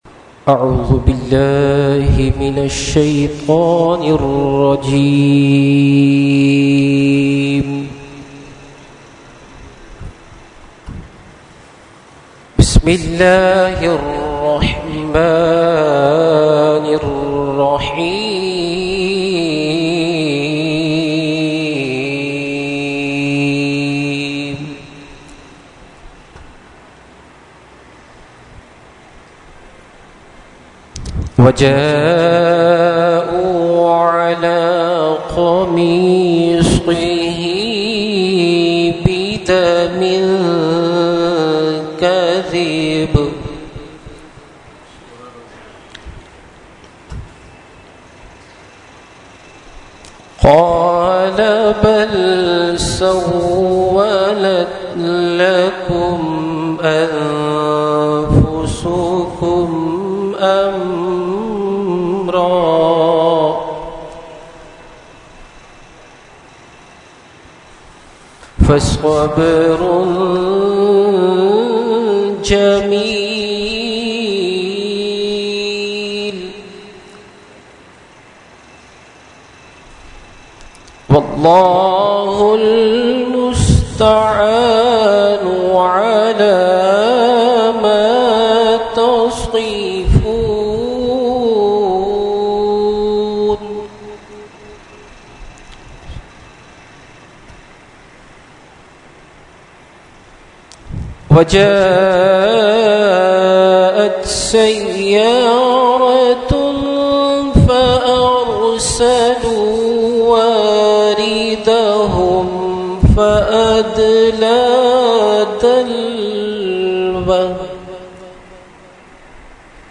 Qirat – Mehfil 11veen Farooqi Masjid 10 March 2011 – Dargah Alia Ashrafia Karachi Pakistan